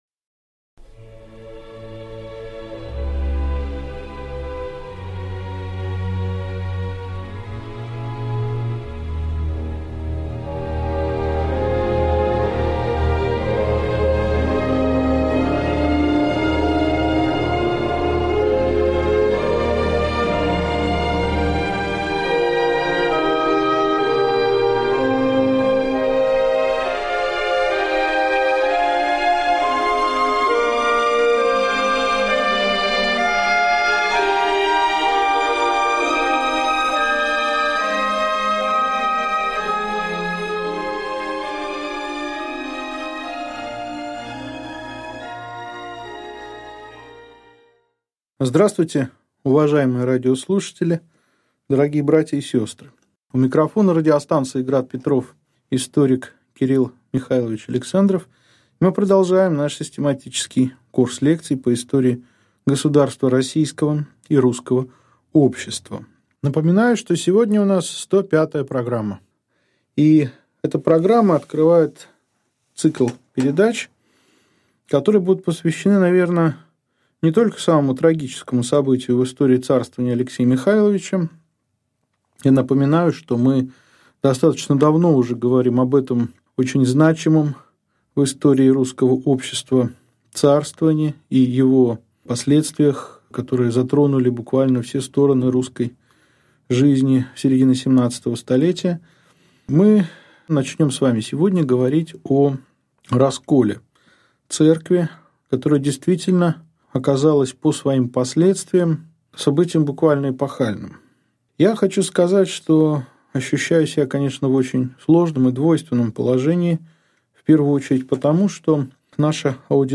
Аудиокнига Лекция 105. Церковный раскол. Историография проблемы | Библиотека аудиокниг